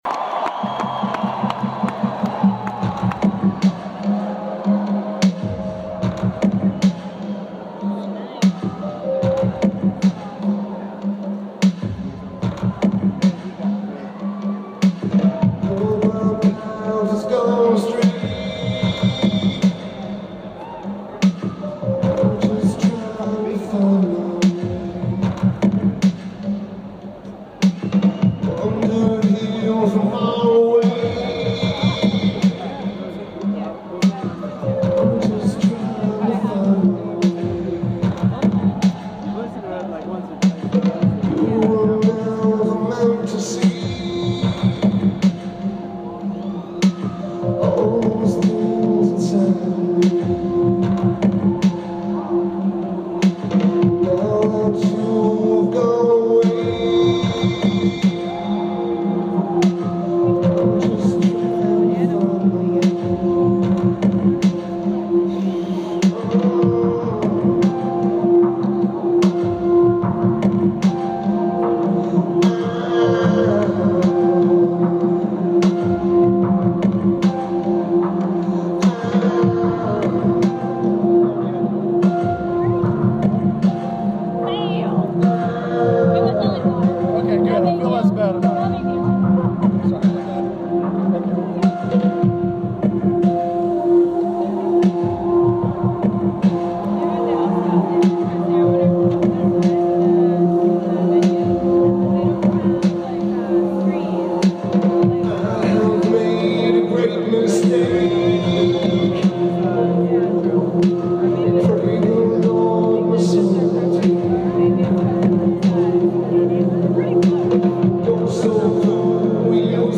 Barclays Center
Lineage: Audio - AUD (iPhone Dictation App)